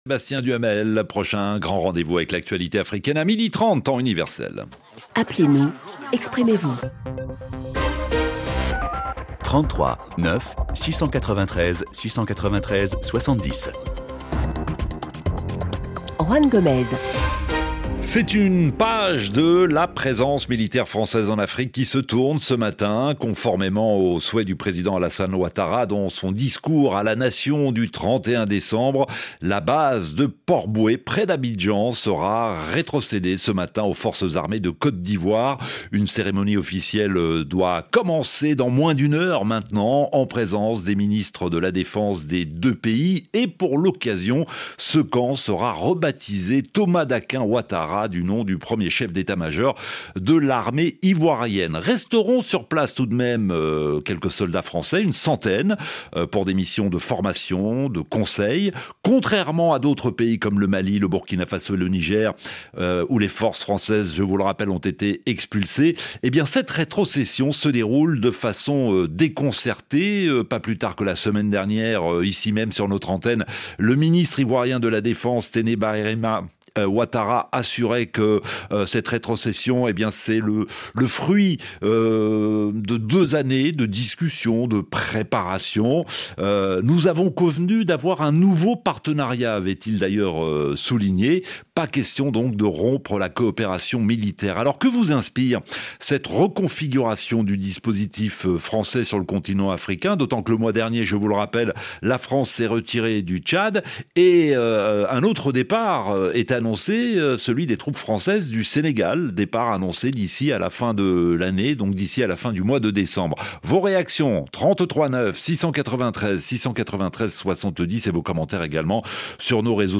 Le rendez-vous interactif des auditeurs de RFI.
L'émission est à la fois un lieu de décryptage de l'information grâce aux questions à la rédaction, mais aussi un lieu de débat où s'échangent idées et réflexions en provenance des cinq continents. Le vendredi, ce sont les auditeurs qui choisissent les sujets sur lesquels ils souhaitent s’exprimer.